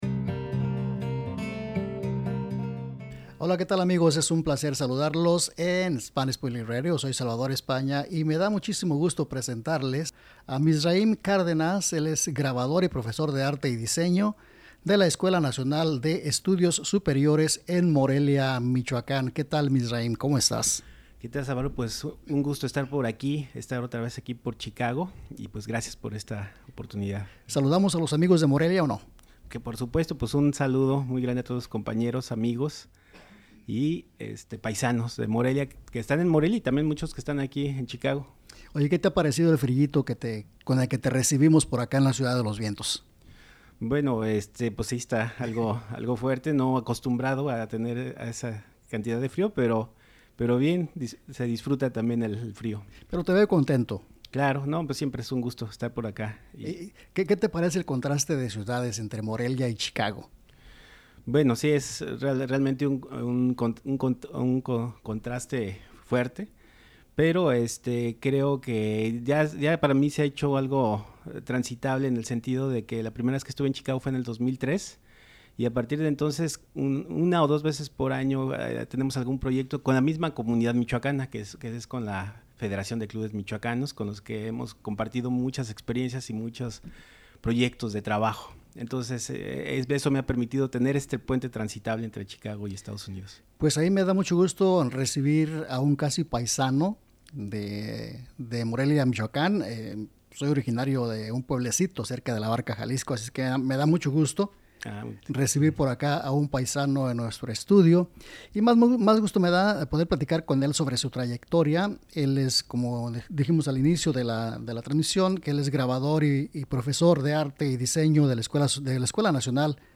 Entrevista
Entrevista al Grabador